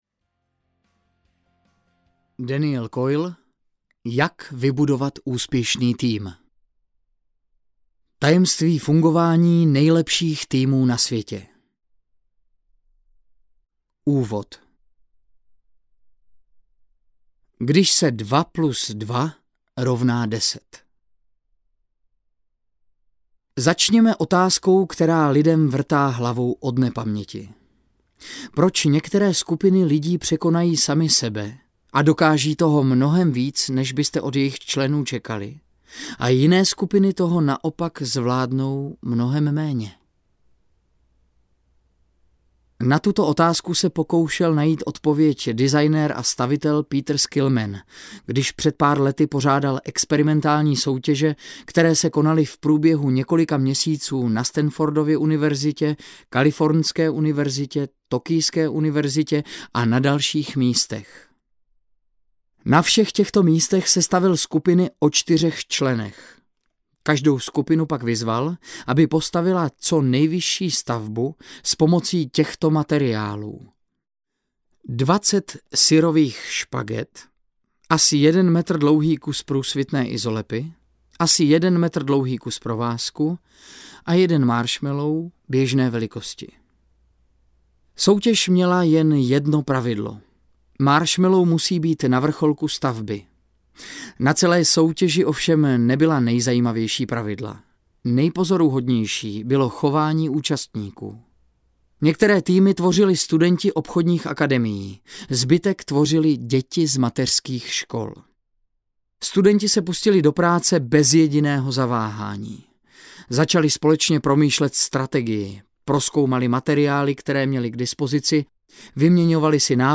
Audio knihaJak vybudovat úspěšný tým
Ukázka z knihy